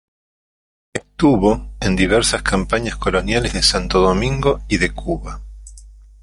Hyphenated as Do‧min‧go Pronounced as (IPA) /doˈminɡo/